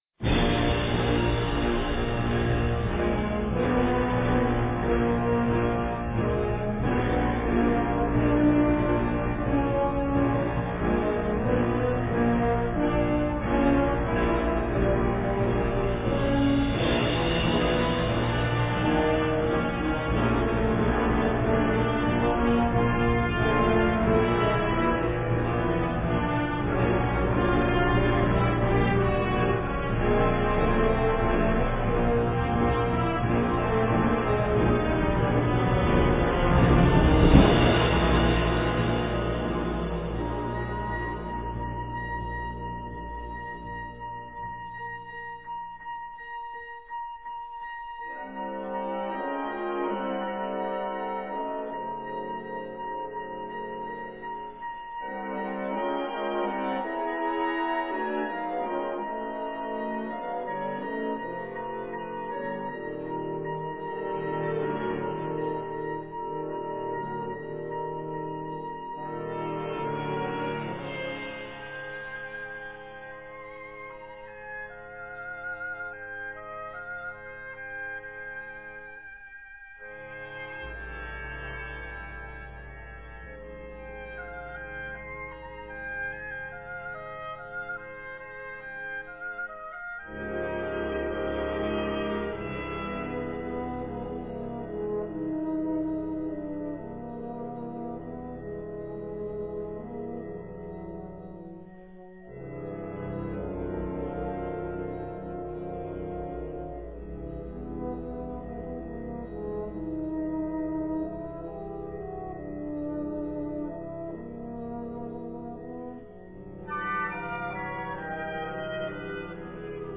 Subcategorie Concertmuziek
Bezetting Ha (harmonieorkest)
Het is levendig, ritmisch intens en vol energie.